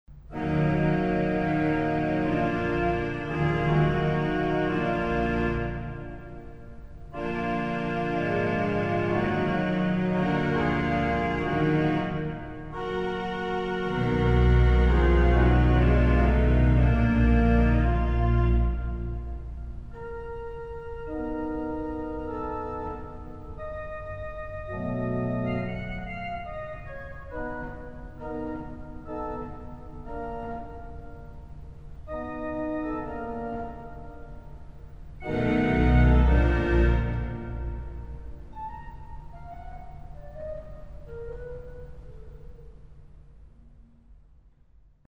Dialogue du Chœur de Voix humaine (Fonds 16, 8, 4, Voix humaine, Tremblant) et du Nazard du Positif, interrompu par deux fois par un Récit de Cornet ; conclusion en decrescendo sur les Flûtes de l’Echo et du Positif.
Positif : Bourdon 8, 2e Flûte 8, Prestant, Nazard
G.O. : Bourdon 16, Montre 8, Flûte 8, Prestant, Voix humaine
Echo : Flûte 8, Bourdon 8
Pédale : Flûte 16, Soubasse 16, Flûte 8, Flûte 4
Positif/G.O., Tremblant doux.